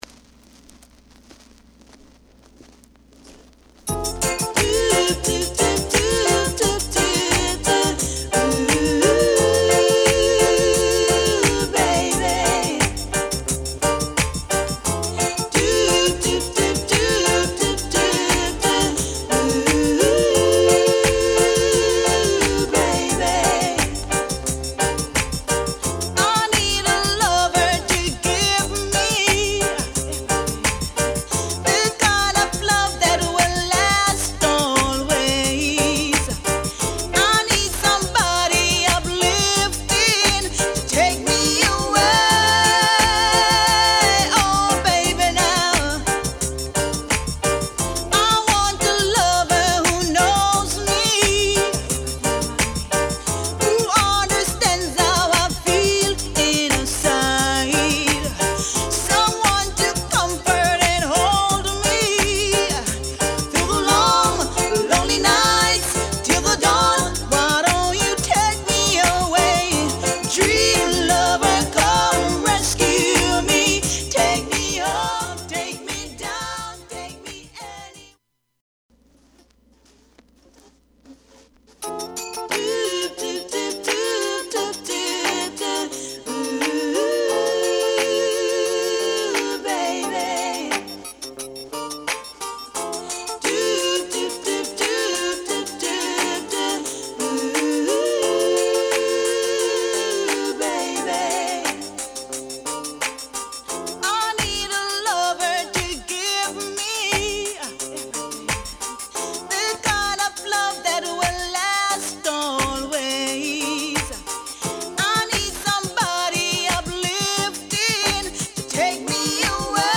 REGGAE / DANCEHALL 盤は擦れや音に影響がある傷がわずかですが有り使用感が感じられます。